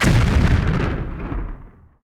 laserBang4.ogg